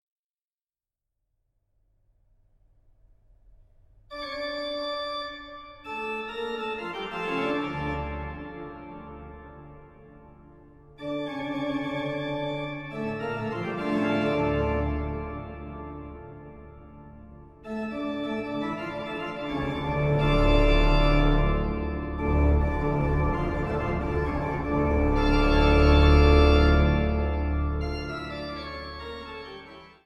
Totentanz-Orgel – Barockorgel – Große Orgel